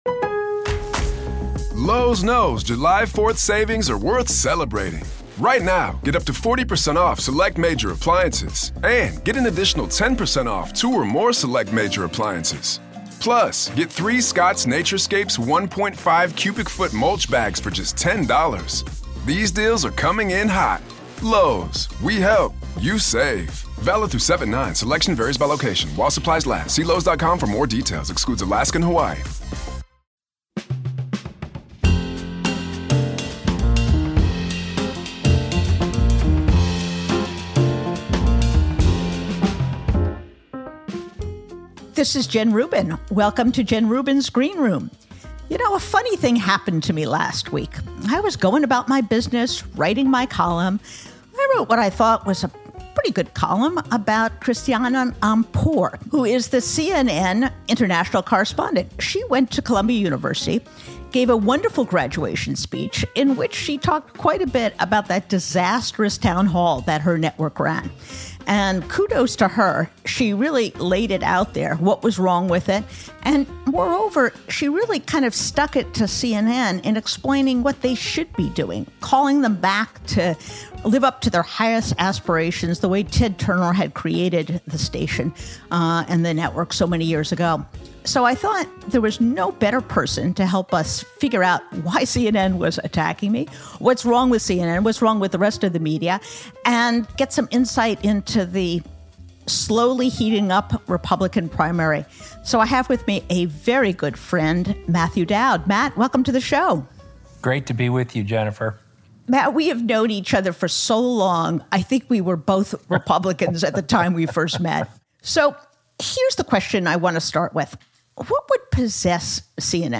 Jen is joined by author, journalist, and political thought leader Matthew Dowd for an insider’s look at the sport-ification of media, and how it leads to political nihilism and rising extremism due to the both sides approach it uses.